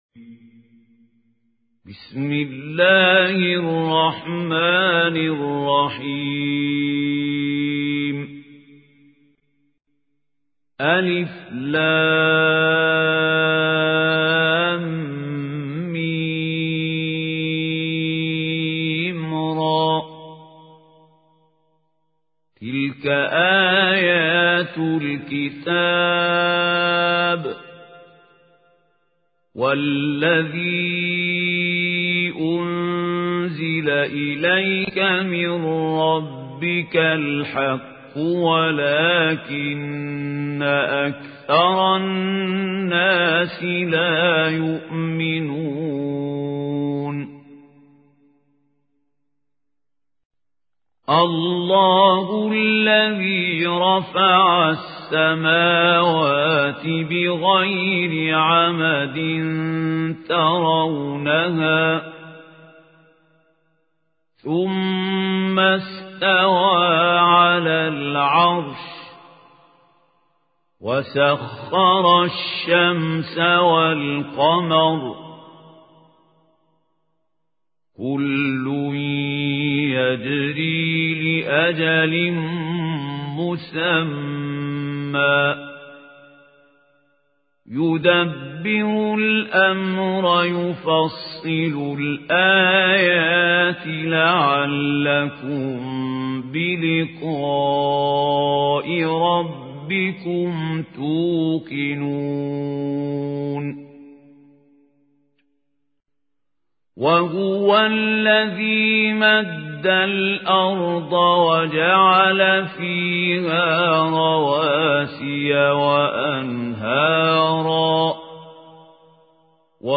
القارئ: الشيخ خليل الحصري